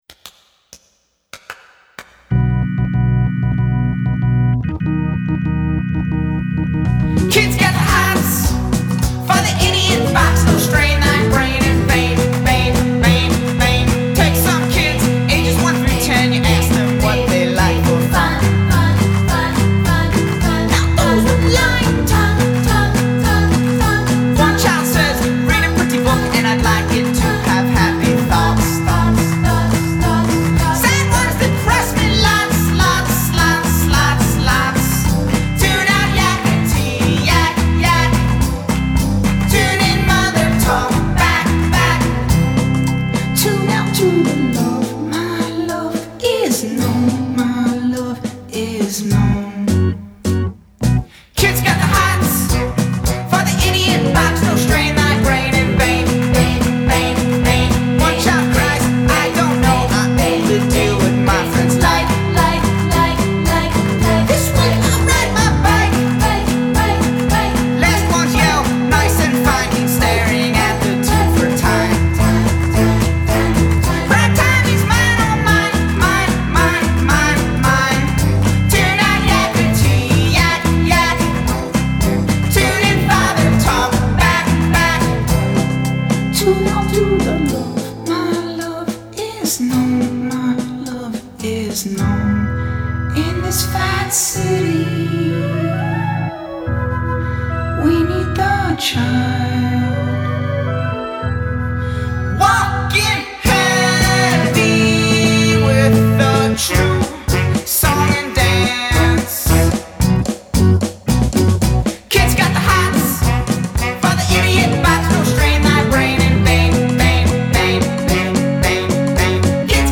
Genres: Indie pop, Acoustic rock
that plays indie pop gospel music.